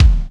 Kick Ambi X5.wav